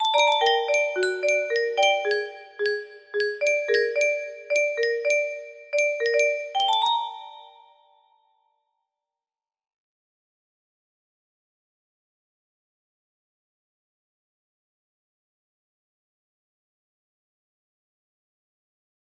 MIDI BOX music box melody